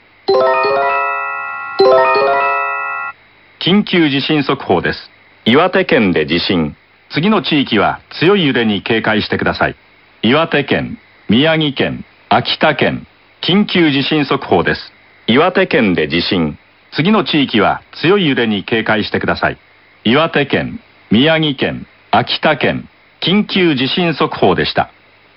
ラジオ放送  ラジオ音声がそのまま放送されます※
※ＮＨＫラジオの放送例